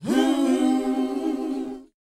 WHOA A#D.wav